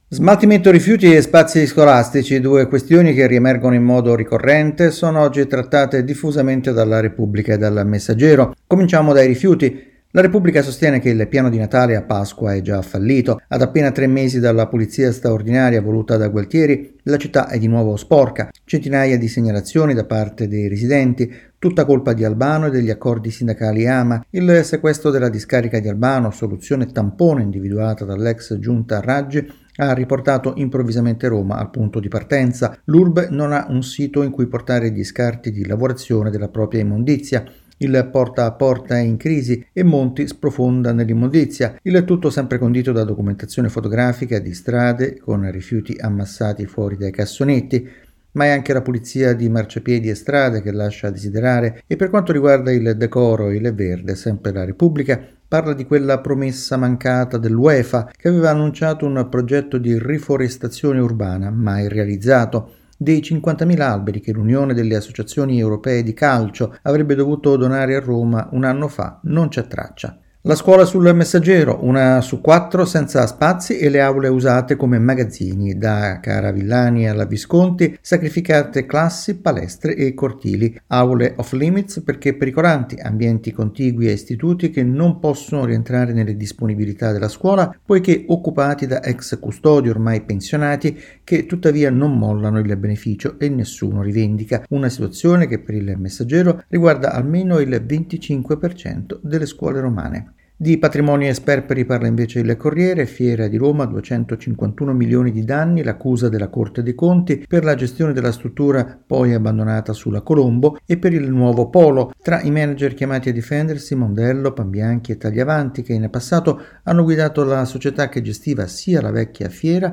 Scuole romane senza aule